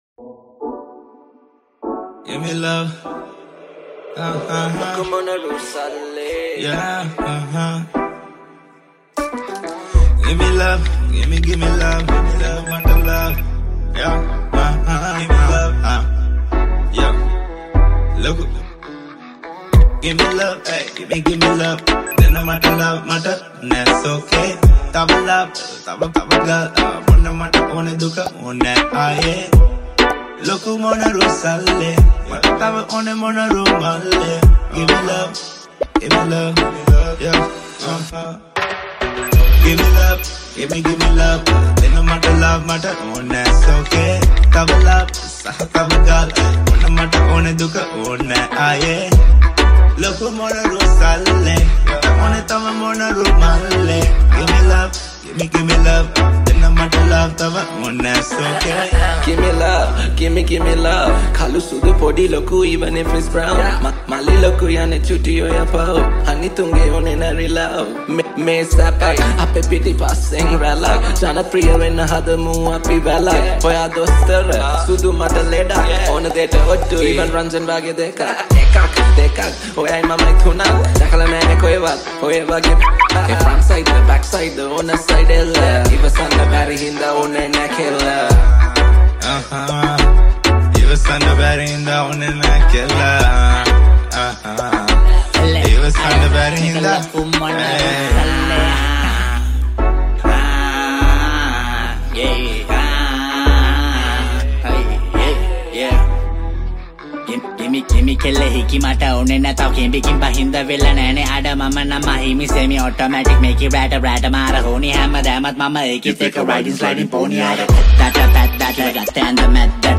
High quality Sri Lankan remix MP3 (3.3).
remix